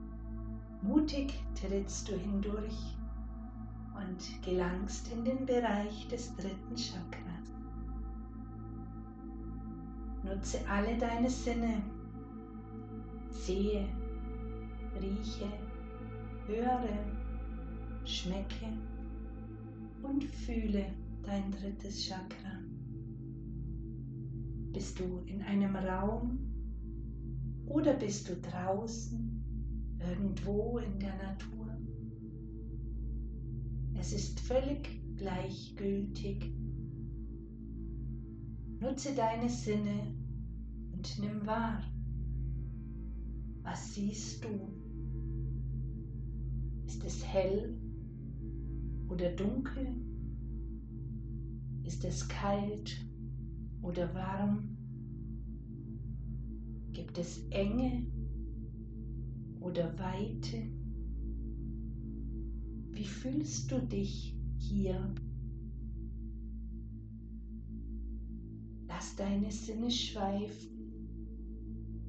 Manipura Solarplexus-Chakra-Meditation